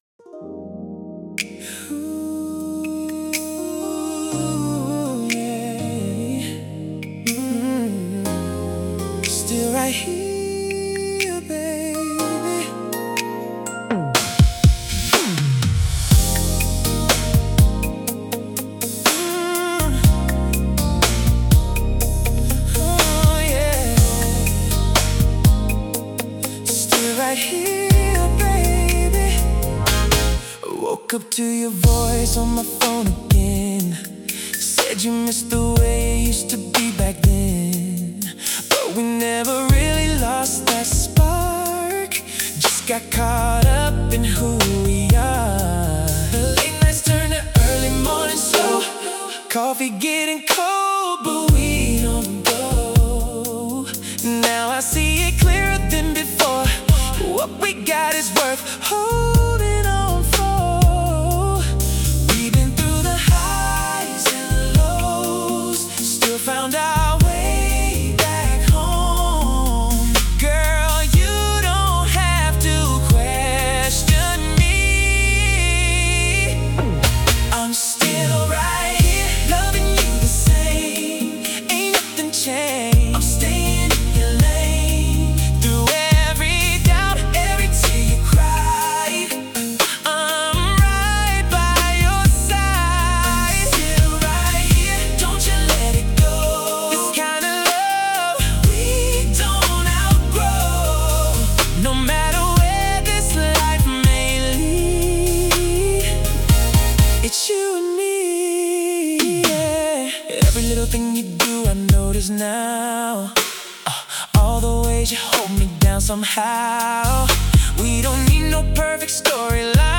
Intimate | Devoted 88 BPM